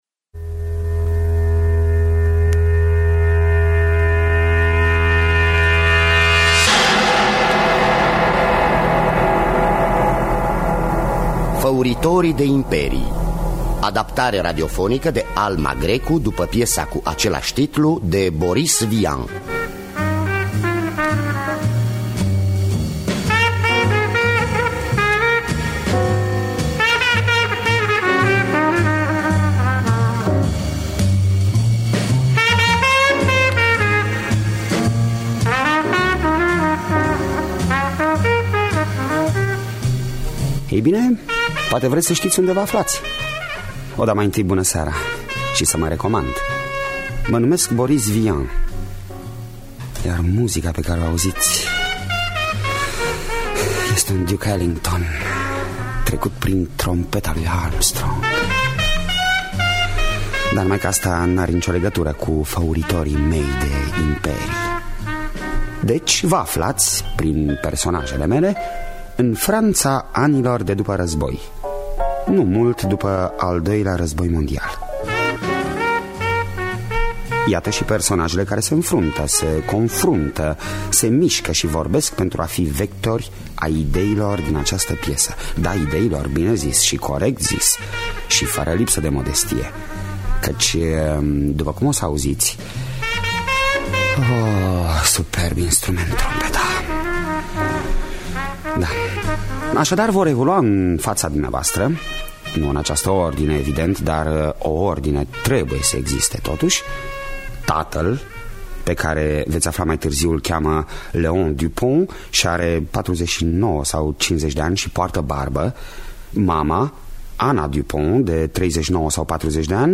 Făuritorii de imperii de Boris Vian – Teatru Radiofonic Online